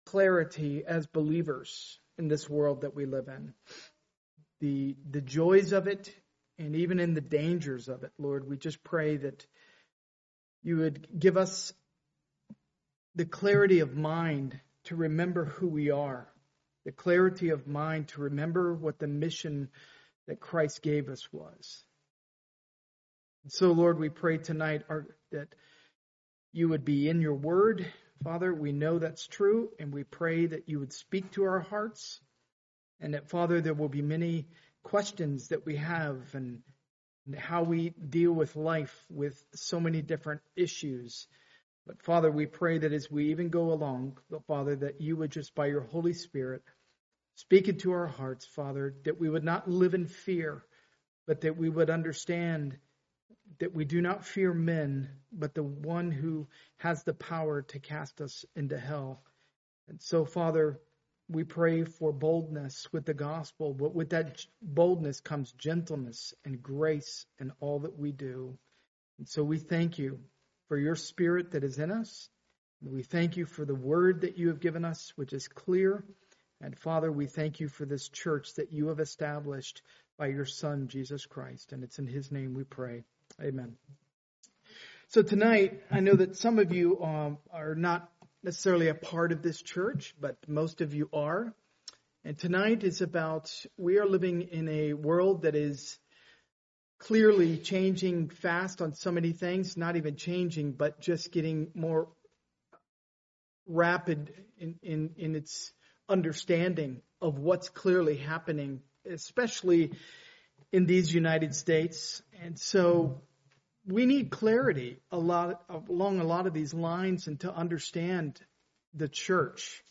Part of the Topical series, preached at a Evening Service service.